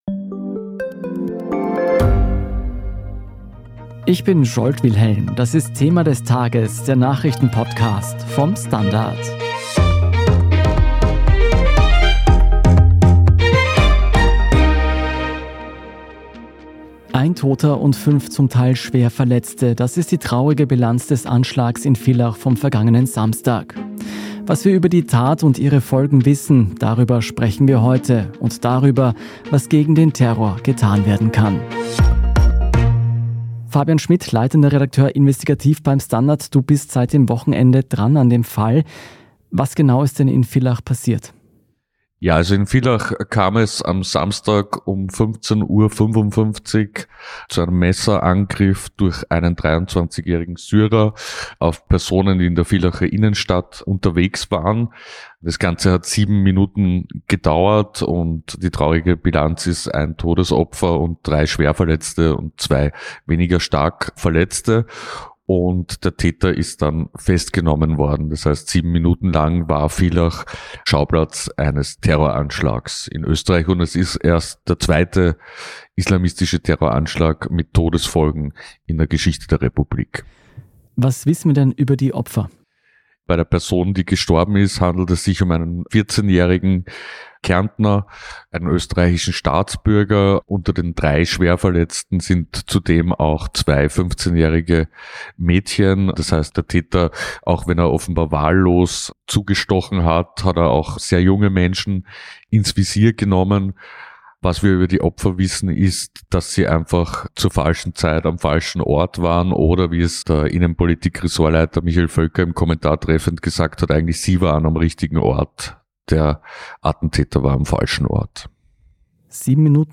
"Thema des Tages" ist der Nachrichten-Podcast vom STANDARD.